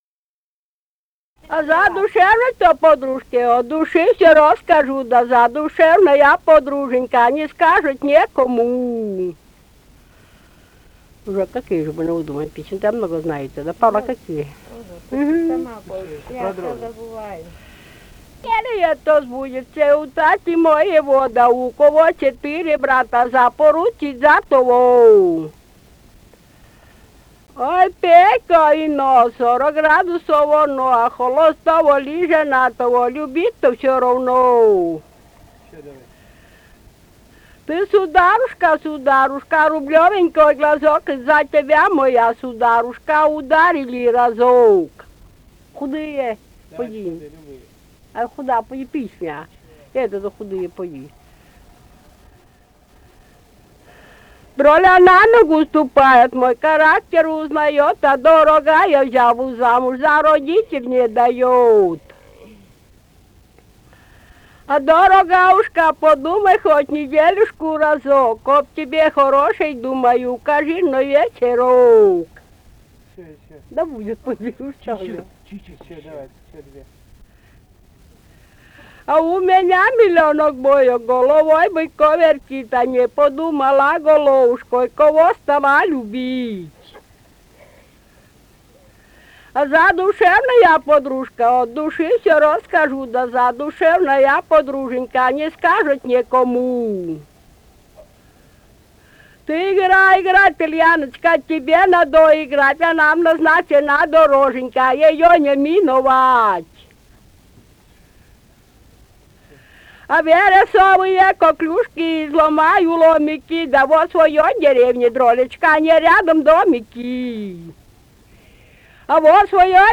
«Задушевной-то подружке» (частушки).